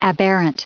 added pronounciation and merriam webster audio
6_aberrant.ogg